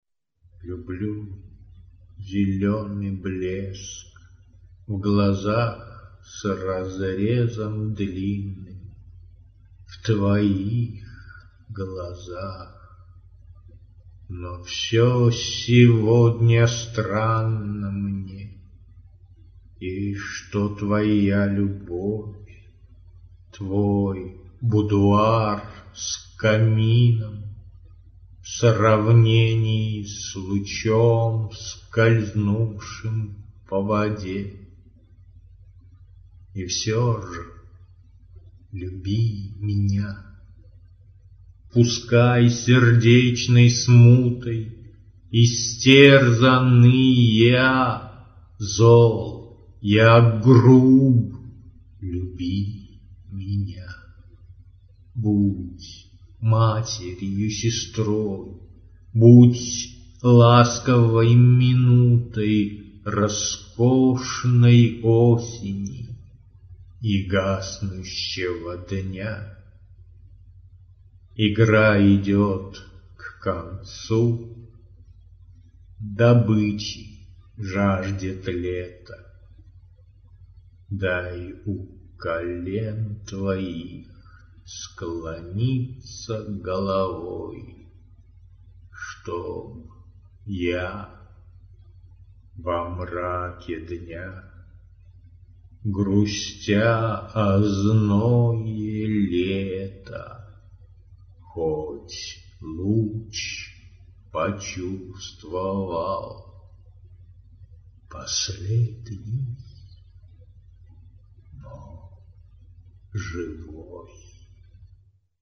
звучащие стихи